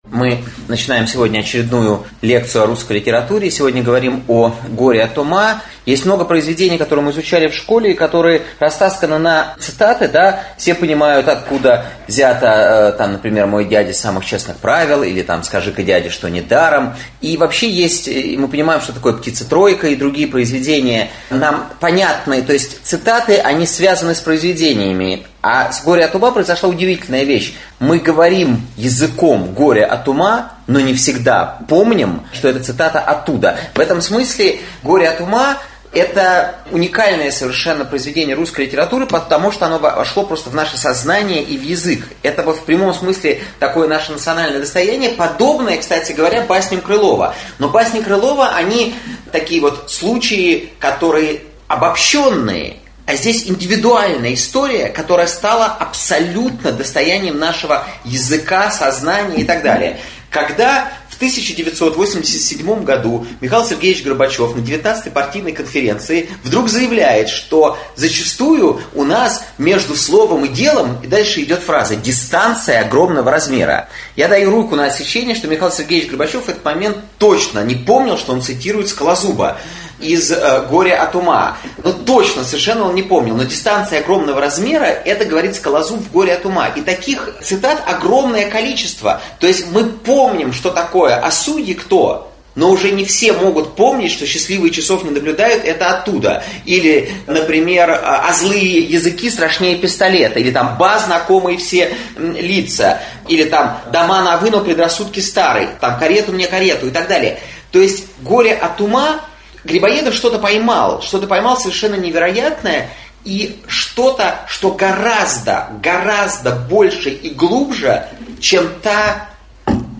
Аудиокнига Не дай мне бог сойти с ума, или почему Чацкий ничего не смог добиться?
Прослушать и бесплатно скачать фрагмент аудиокниги